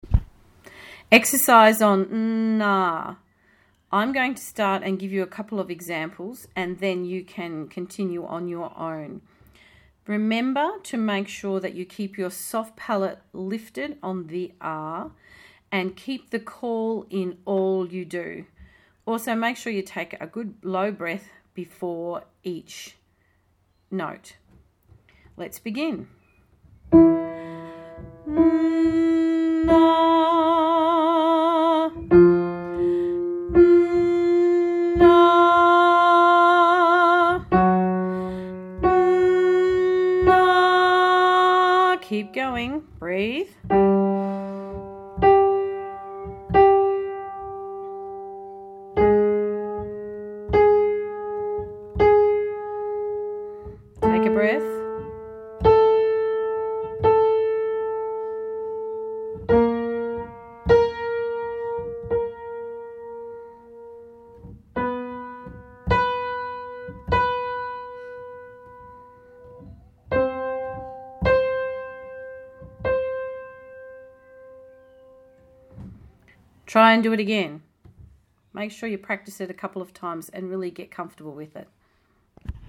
Singing Exercises
1. N-ah.  When you start this exercise feel the buzz on the hum and then open to an ahh.
n-ah.mp3